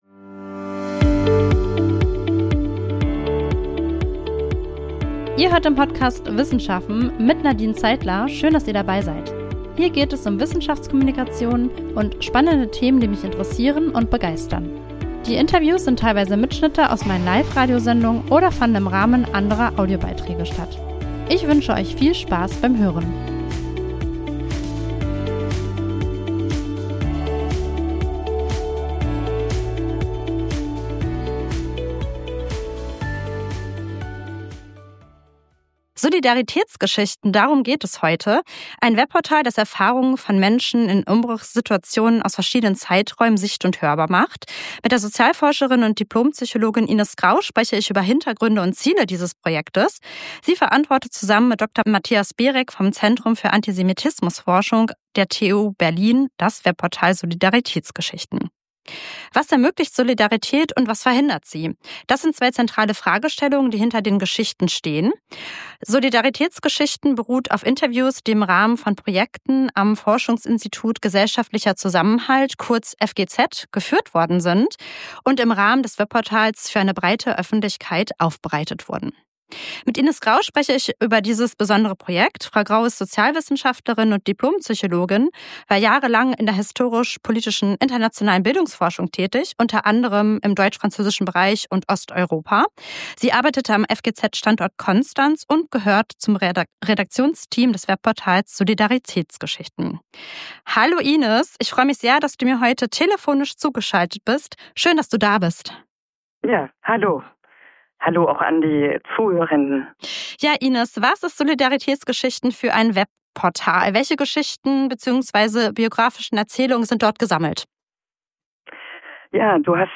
Mit der Sozialforscherin und Dipl.-Psychologin